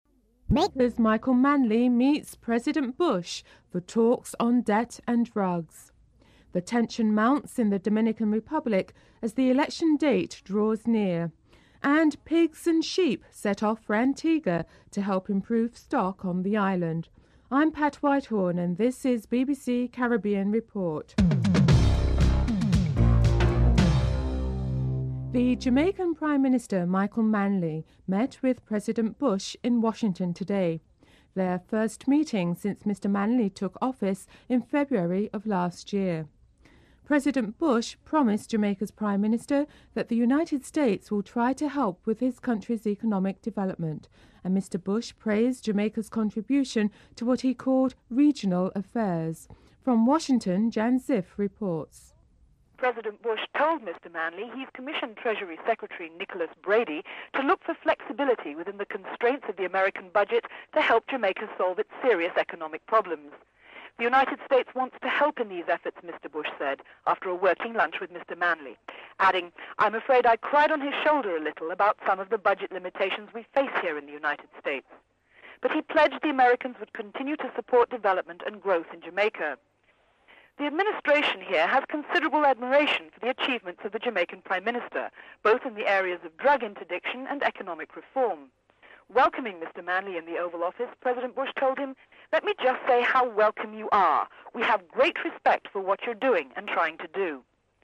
The British Broadcasting Corporation
1. Headlines (00:00-00:25)